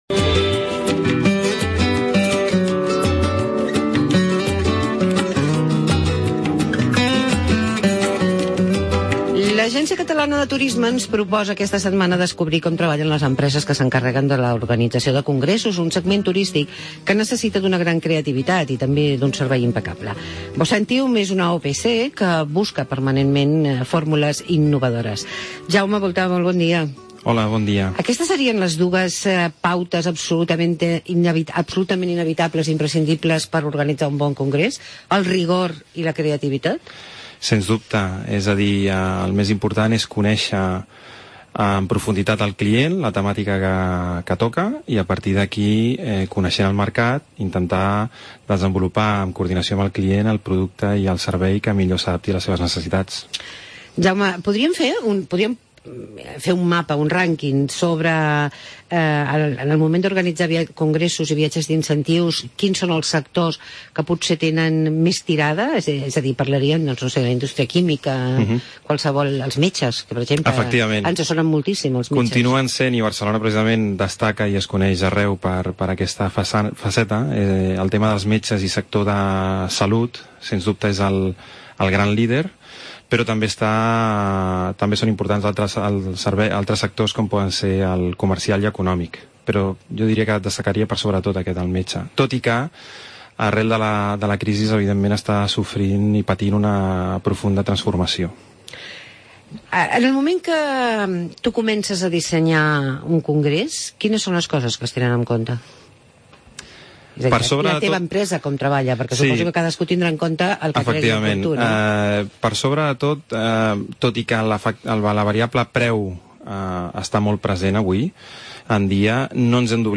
Tira Milles L' ACT ens proposa conèixer l'activitat de BOCEMTIUM una empresa que es dedica a l'organització de congressos. Entrevista